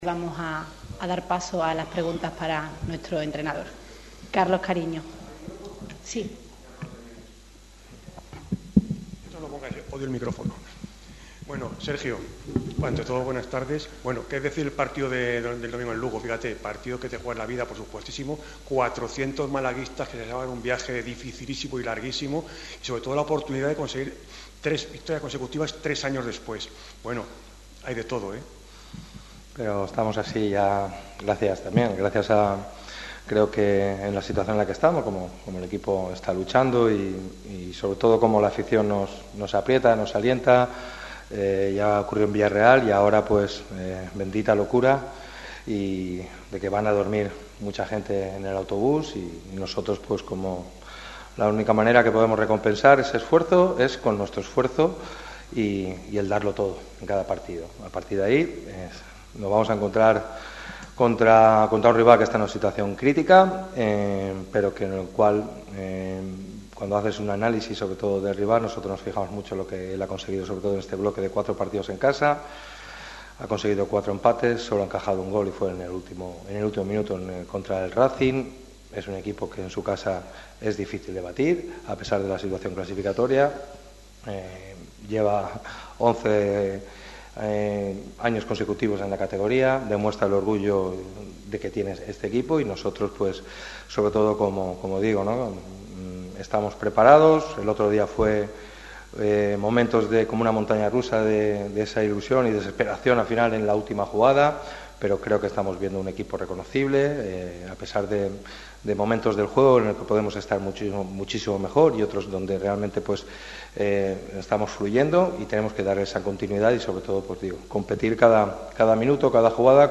Este viernes pasó por rueda de prensa el técnico malaguista antes de viajar a tierras gallegas.
Este viernes ha comparecido en sala de prensa Sergio Pellicer. El técnico malaguista habló en la previa del Lugo-Málaga del próximo domingo.